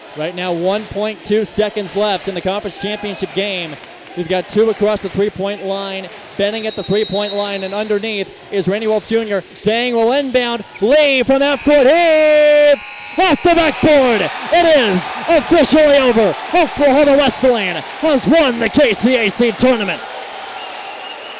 Here's the final call on KPGM.
KCAC Final Call (3).mp3